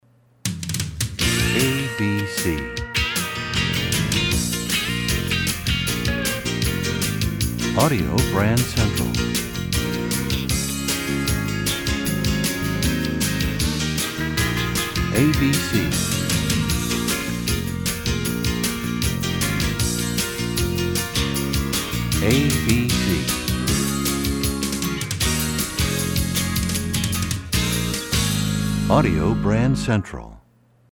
Genre: Jingles.